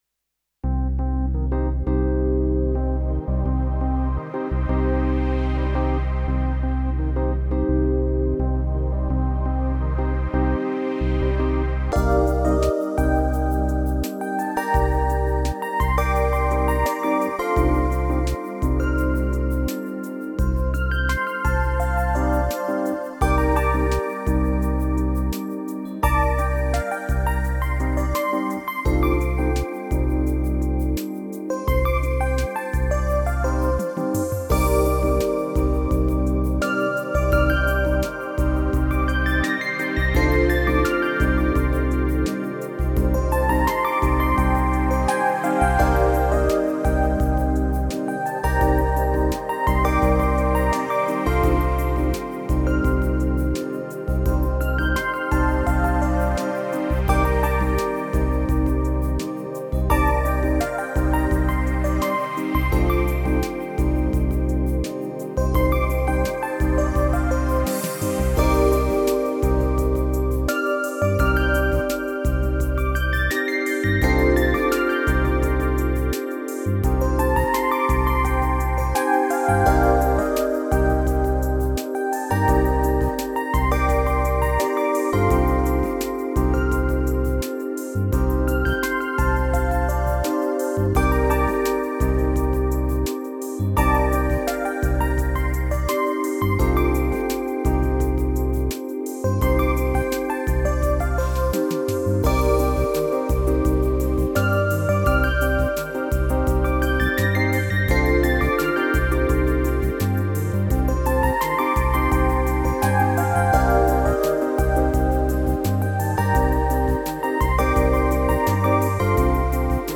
Home > Music > Jazz > Bright > Medium > Dreamy